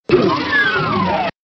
doom-door_26264.mp3